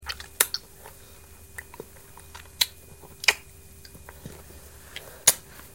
babyeat.ogg